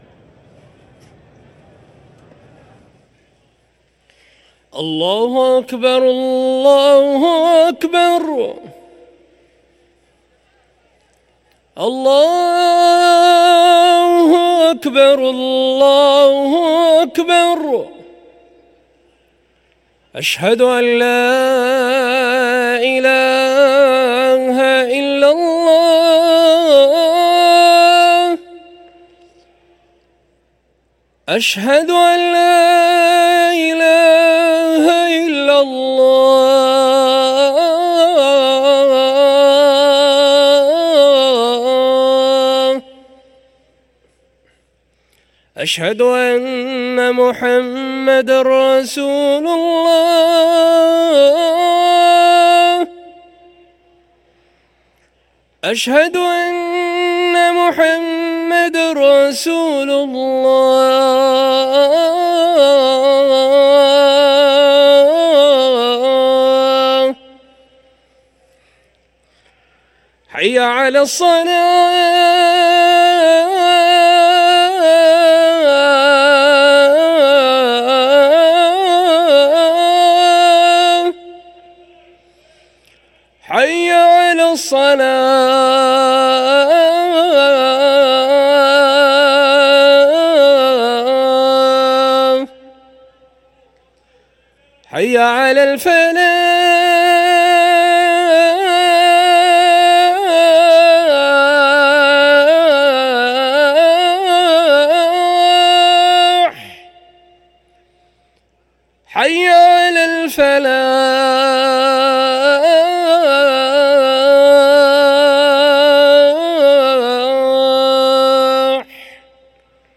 أذان الظهر للمؤذن حمد دغريري الأربعاء 29 محرم 1445هـ > ١٤٤٥ 🕋 > ركن الأذان 🕋 > المزيد - تلاوات الحرمين